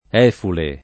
Efule [ $ fule ] top. stor. (Lazio)